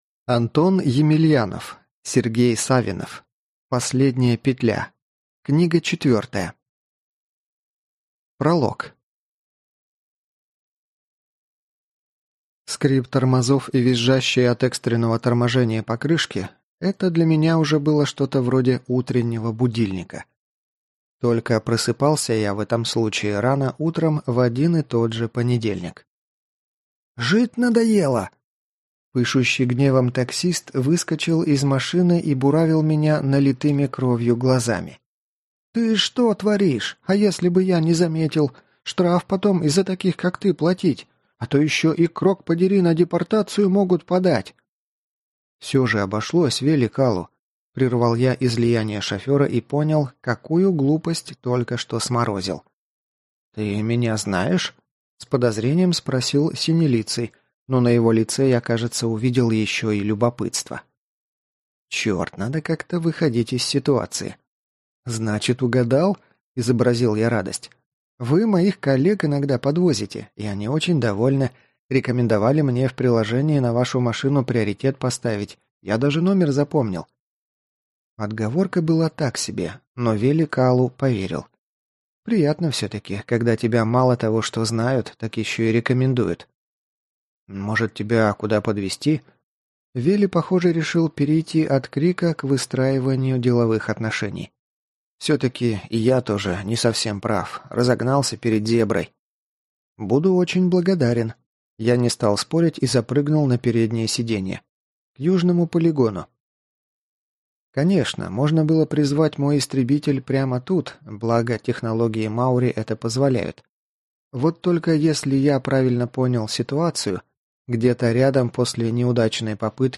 Аудиокнига Последняя петля. Книга 4 | Библиотека аудиокниг